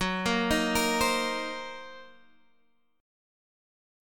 F#6b5 chord